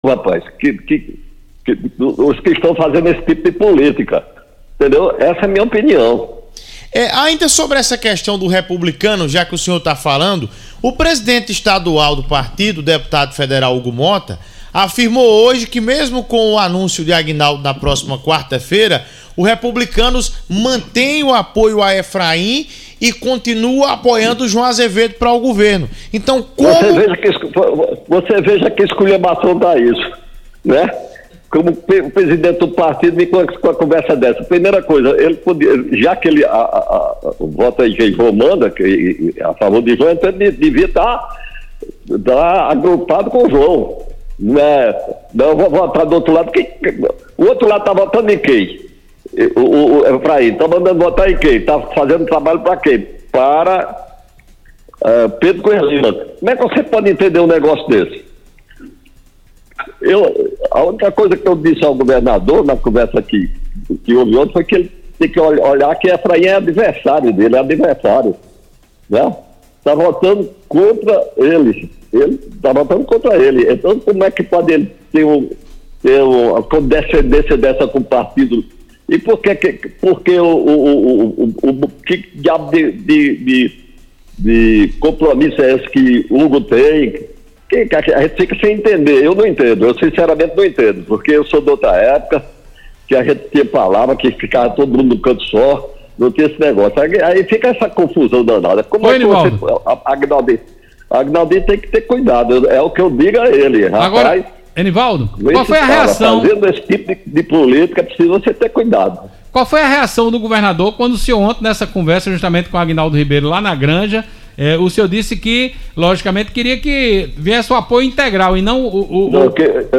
Em entrevista no início da noite desta segunda-feira (13), o presidente estadual do Progressistas, Enivaldo Ribeiro, pai do deputado Aguinaldo Ribeiro disse que apoio do Republicanos ao deputado Efraim Filho ao Senado é uma ‘esculhambação’.